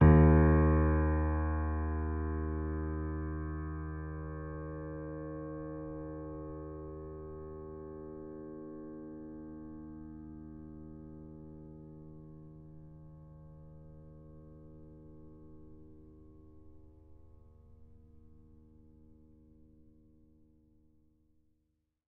sampler example using salamander grand piano
Ds2.ogg